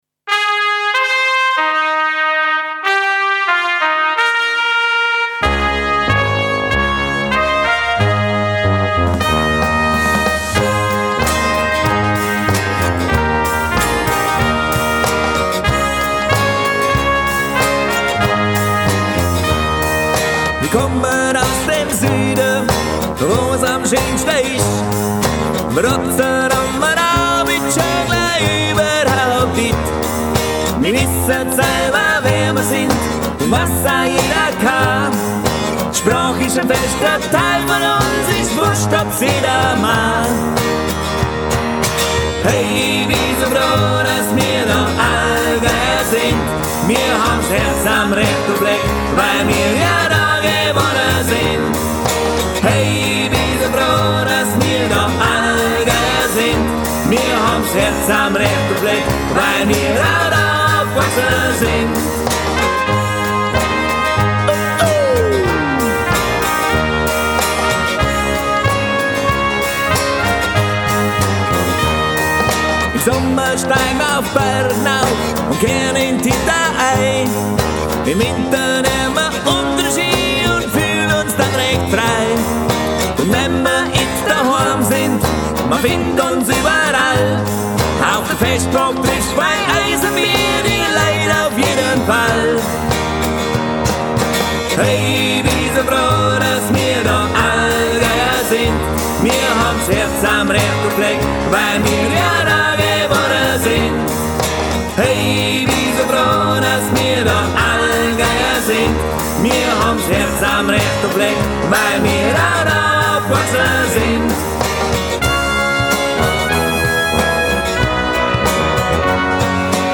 Demosong
Aufnahmesessions (Demo-Aufnahmen) bei mir im Home-Studio: